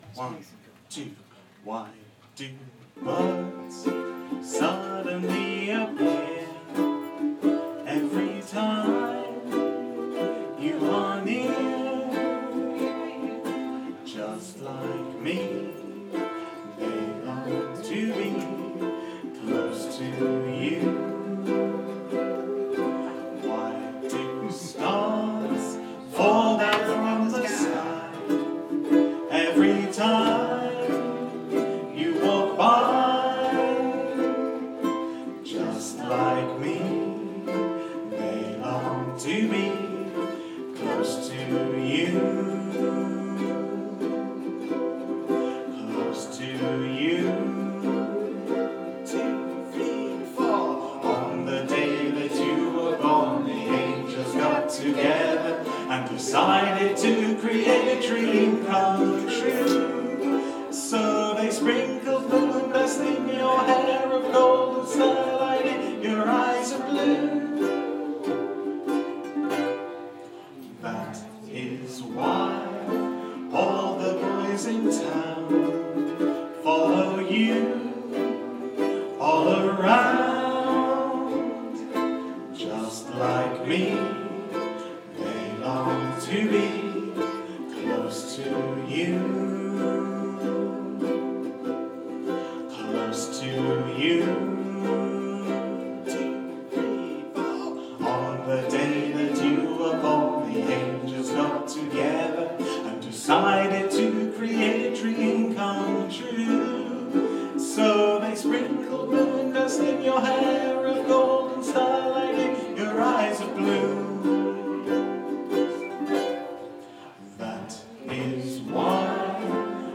West End Class at The Lion